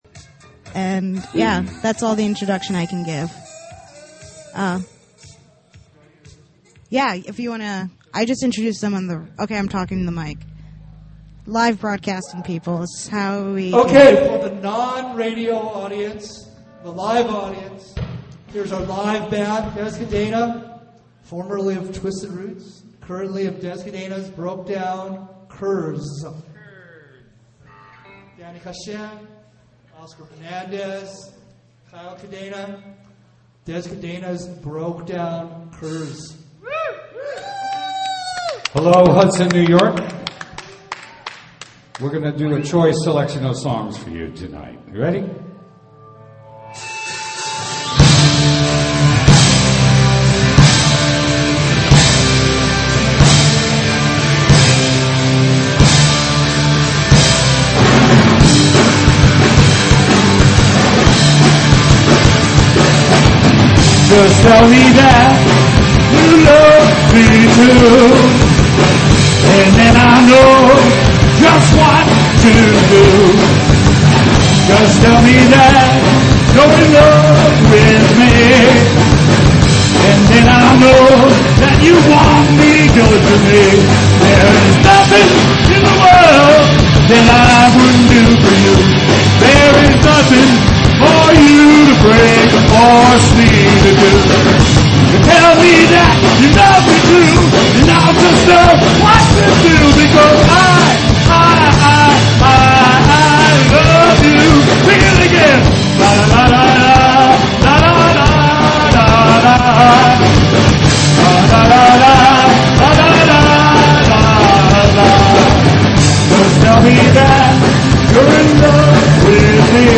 Basilica Hudson